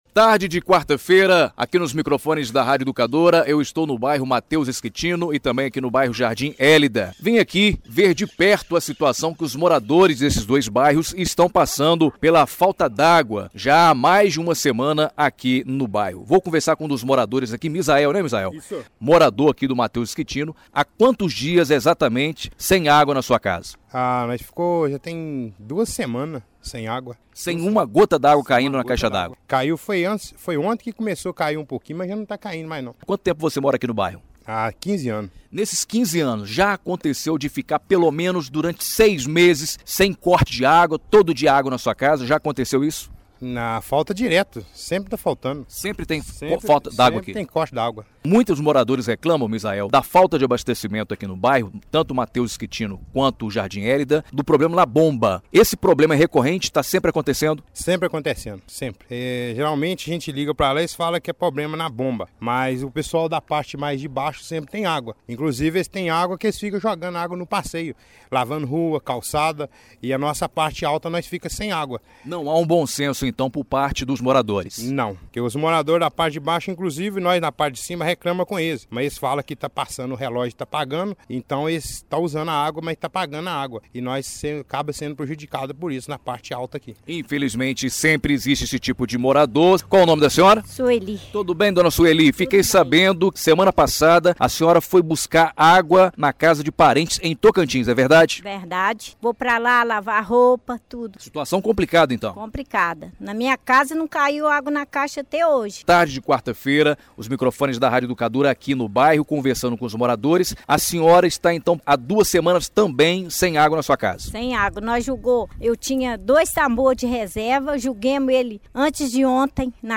áudio exibido na Rádio Educadora AM/FM – Jornal Em dia com a notícia